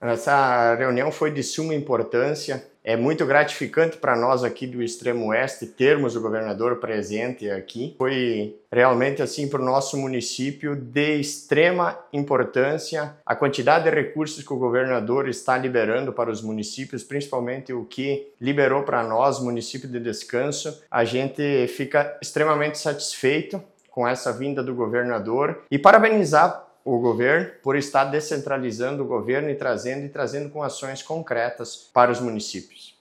O prefeito ressalta a importância da quantidade de recursos que estão sendo liberados: